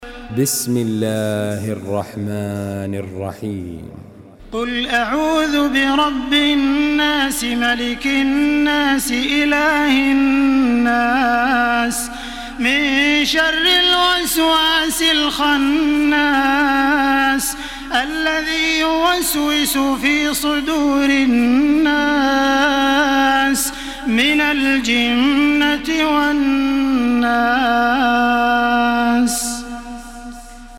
تحميل سورة الناس بصوت تراويح الحرم المكي 1433
مرتل حفص عن عاصم